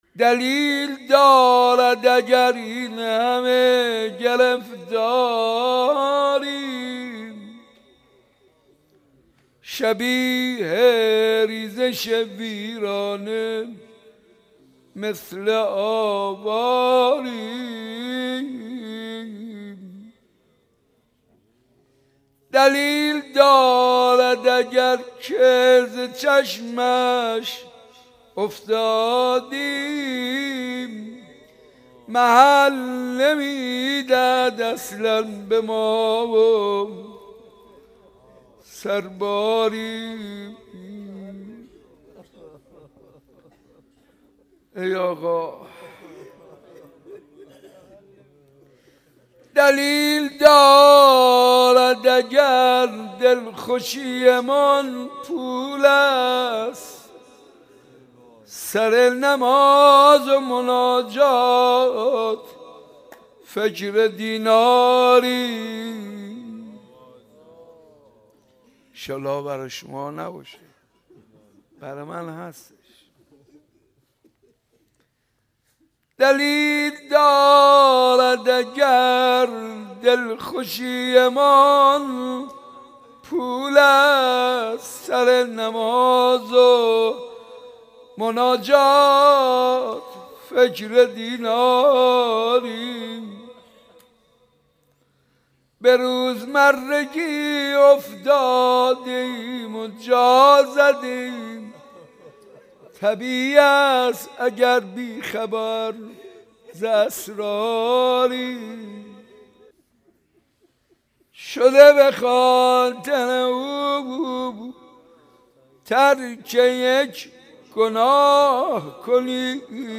دلیل دارد اگر این همه گرفتاریم | مناجات با امام زمان
حسینیه ی صنف لباس فروش ها